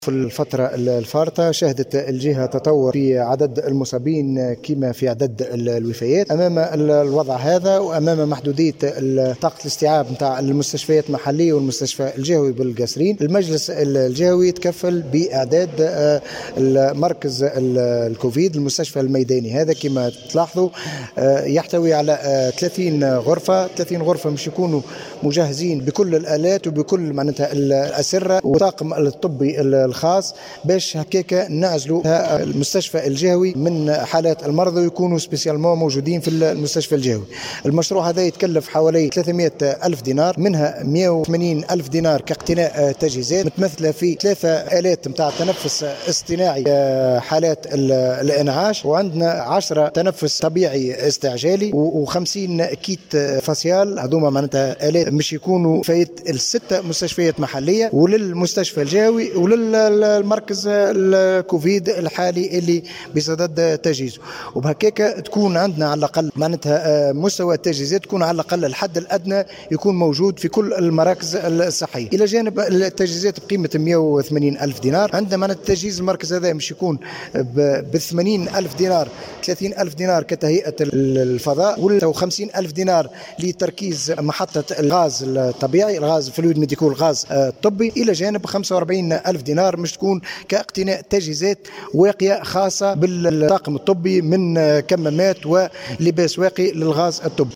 اكثر تفاصيل في التسجيل التالي لكاتب عام الولاية التوهامي المحمدي